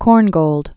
(kôrngōld, -gôlt), Erich Wolfgang 1897-1957.